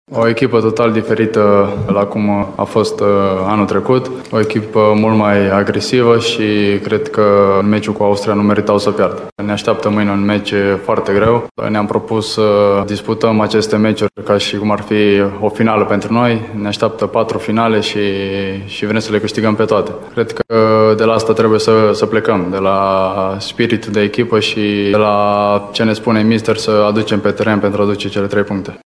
Mijlocașul timișorean Marius Marin, de la formația italiană Pisa a lăudat gruparea insulară: